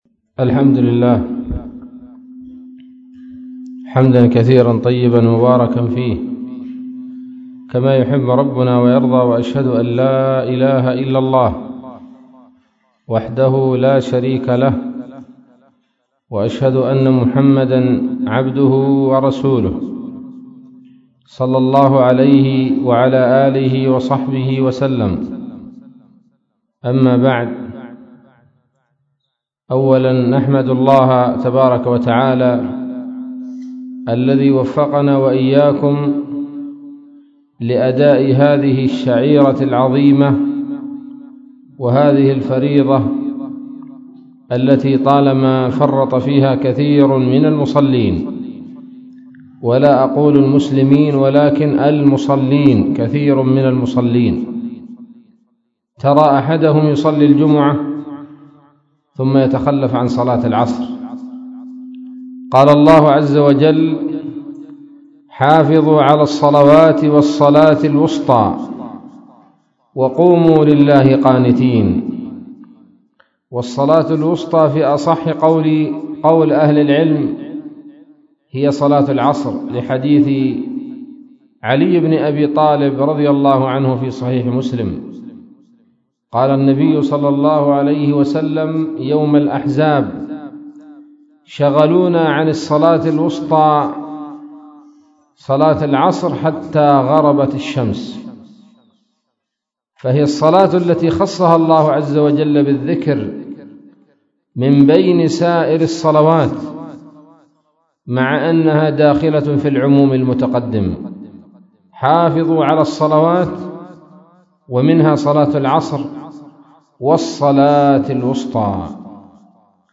كلمة قيمة بعنوان:((ما خلقنا للعبث)) عصر يوم الجمعة 2 جمادى الأولى 1439هـ بقرية الرجاع